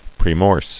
[prae·morse]